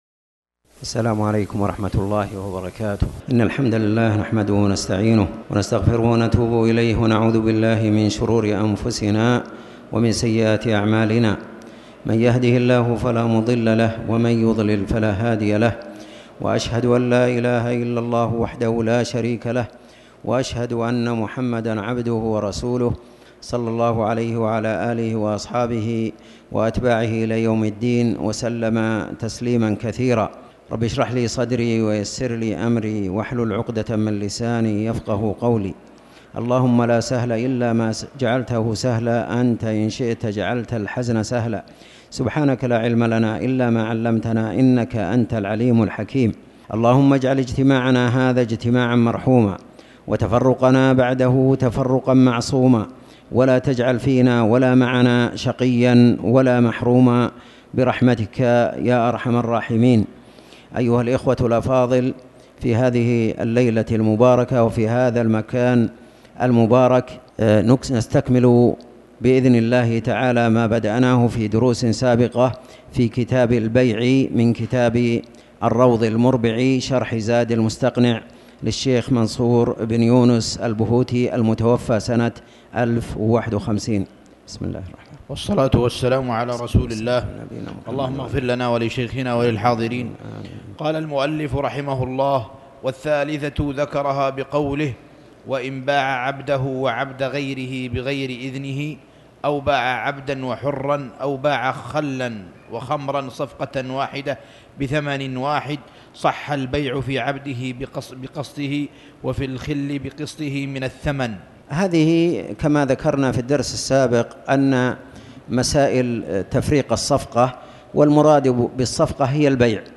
تاريخ النشر ٢٧ جمادى الأولى ١٤٣٩ هـ المكان: المسجد الحرام الشيخ